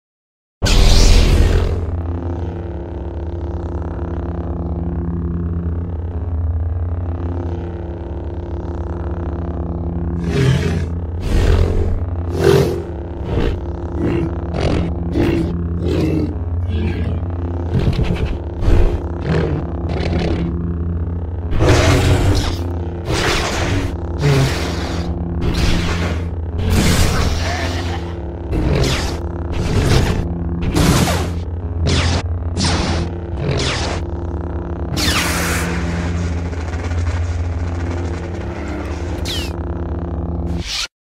Звуки лазерного меча
Включение лазерного меча, взмахи и выключение